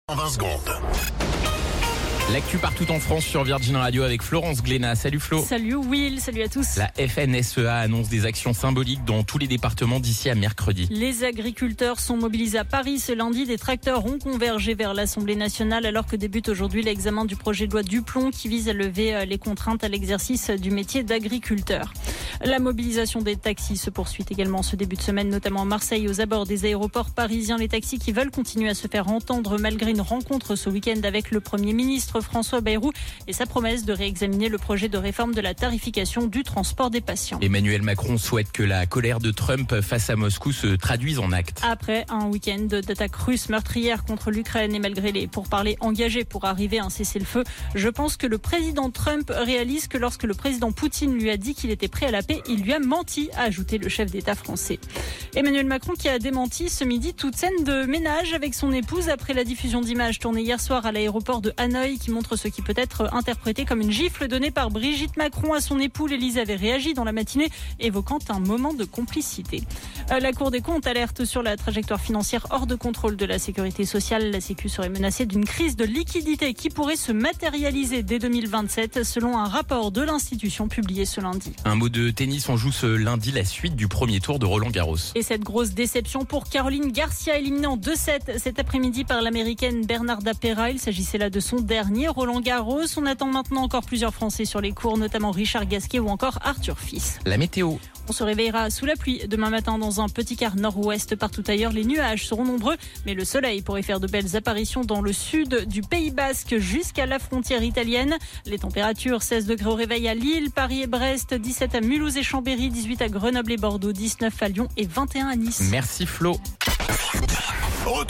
Flash Info National 26 Mai 2025 Du 26/05/2025 à 17h10 .